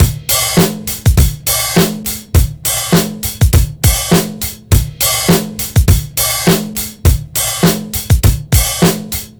TR BEAT 2 -L.wav